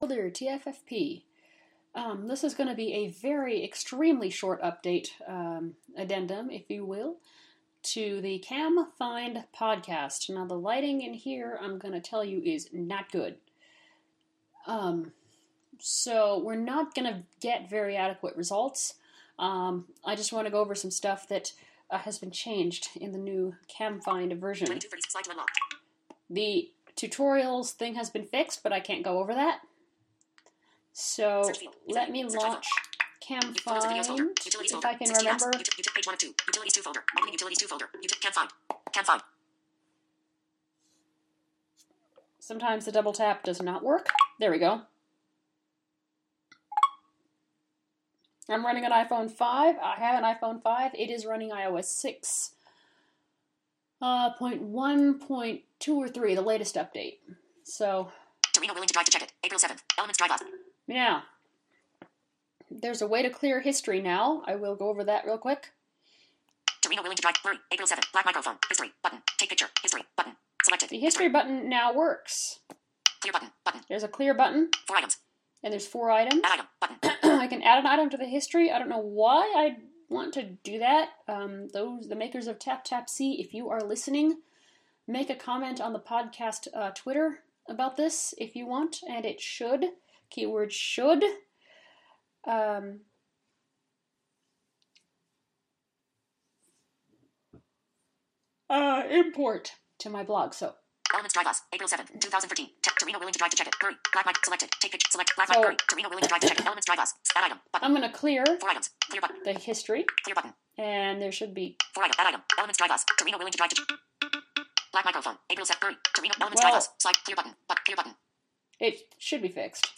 And sorry I sound sick.